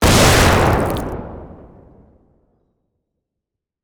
CosmicRageSounds / wav / general / combat / weapons / rocket / flesh3.wav
flesh3.wav